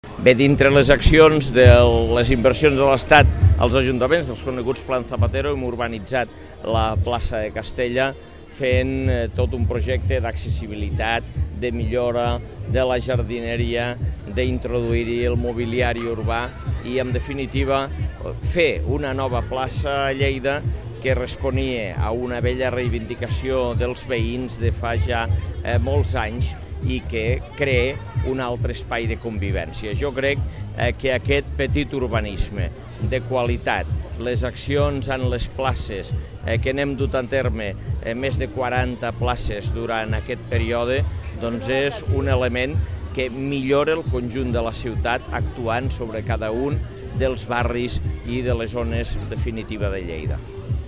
tall-de-veu-dangel-ros-sobre-la-inauguracio-de-la-placa-de-castella